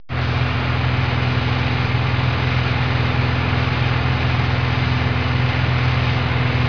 دانلود آهنگ طیاره 56 از افکت صوتی حمل و نقل
دانلود صدای طیاره 56 از ساعد نیوز با لینک مستقیم و کیفیت بالا
جلوه های صوتی